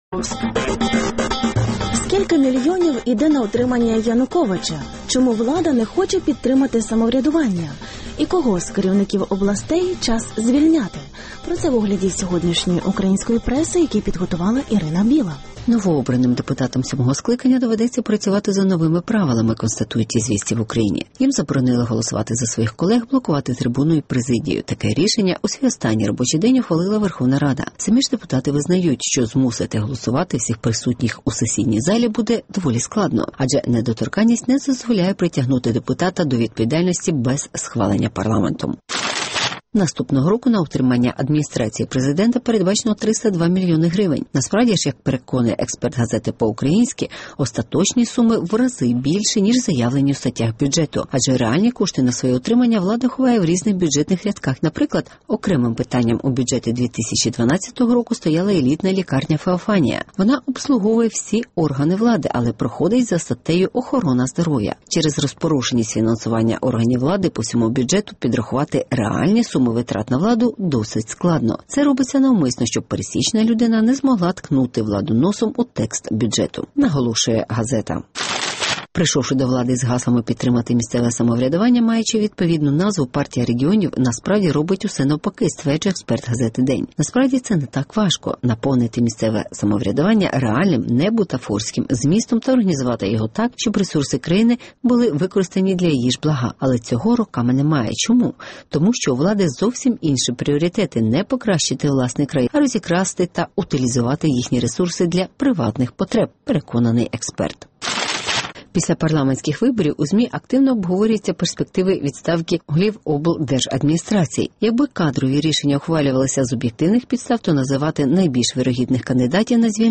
Скільки мільйонів йде на утримання Януковича? (Огляд преси)